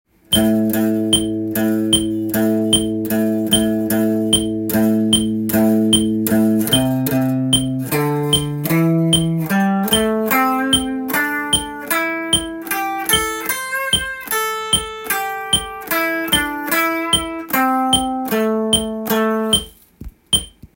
スケールでリズム練習tab
⑥のリズム裏拍が連続して登場するので